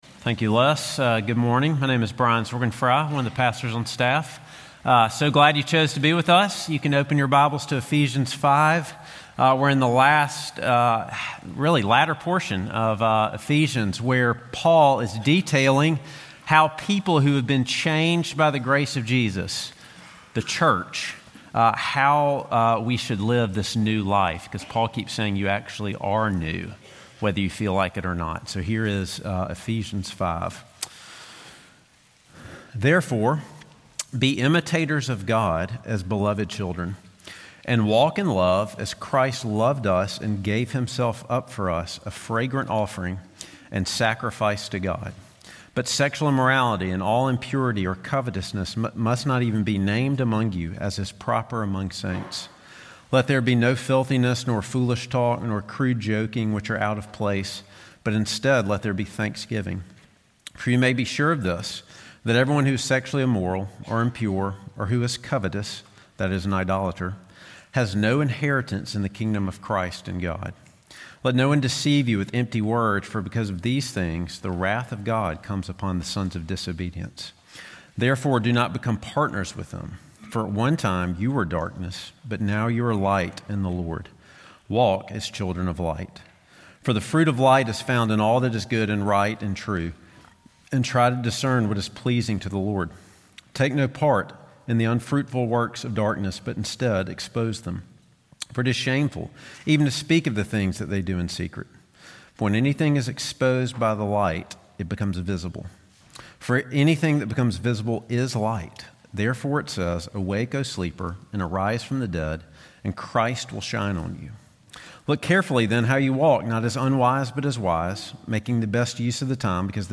Sermon Points